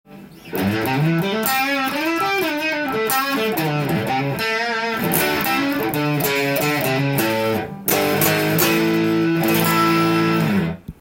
歪ませてもかなりカッコいいロックなビンテージサウンド。